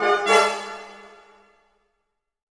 Index of /90_sSampleCDs/Best Service ProSamples vol.33 - Orchestral Loops [AKAI] 1CD/Partition B/128 VERTIGO